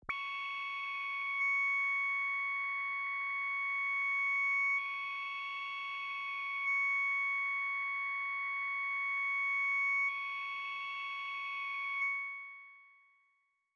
描述：通过Modular Sample从模拟合成器采样的单音。
Tag: CSharp6 MIDI音符-85 罗兰木星-4 合成器 单票据 多重采样